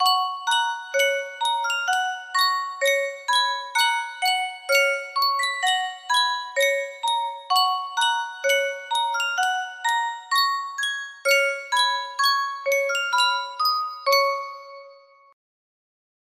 Sankyo Music Box - Hark the Herald Angels Sing -G music box melody
Full range 60